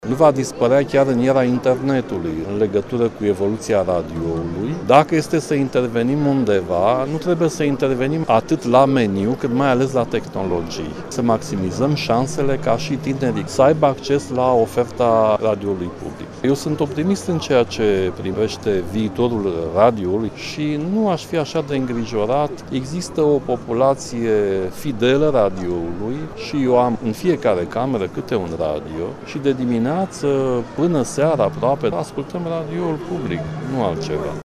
Mii de români au trecut, şi ieri, pragul Târgului de carte Gaudeamus – Carte de învăţătură, organizat de Radio România, la Pavilionul Central Romexpo, din capitală. Această ediţie, a 25-a, este dedicată Centenarului Marii Uniri şi împlinirii a 90 de ani de existenţă a postului public de radio. În cadrul unei mese rotunde